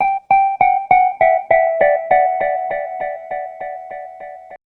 Cudi Synth 1.wav